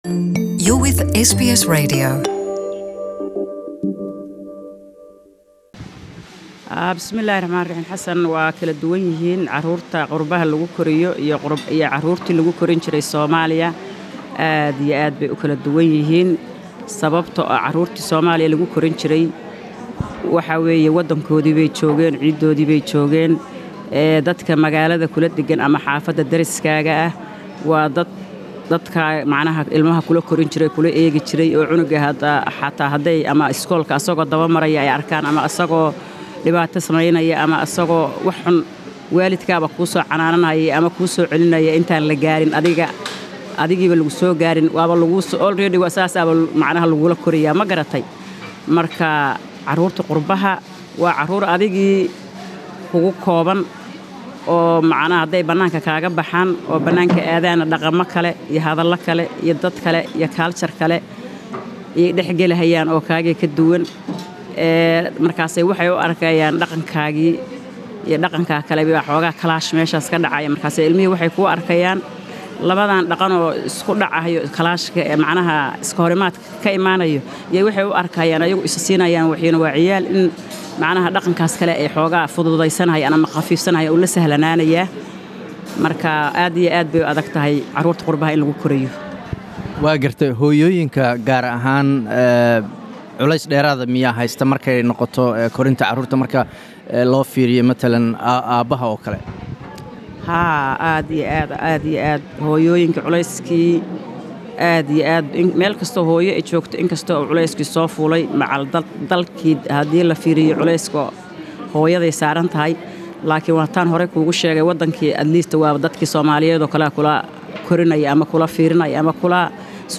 Interview with Somali Mother about community issues.